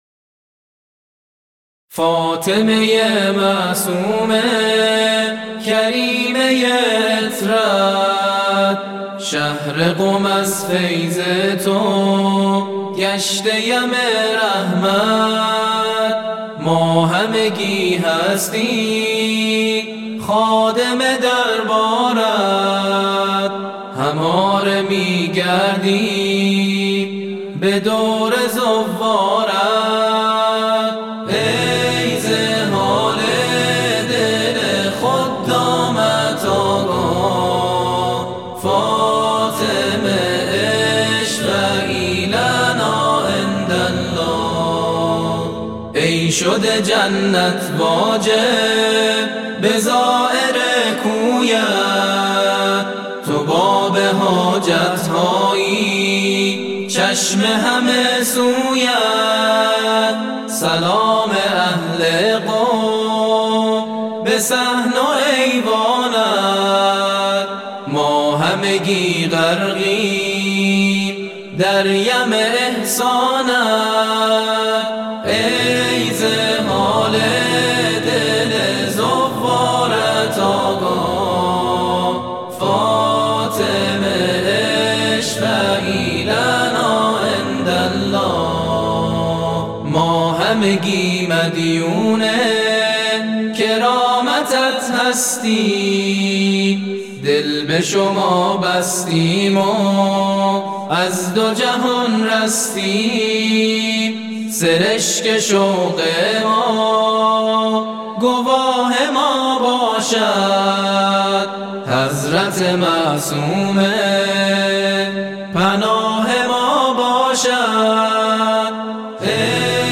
نغمات آئینی